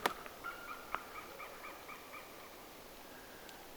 merikotkan ääntä, 2
merikotkan_aanta2.mp3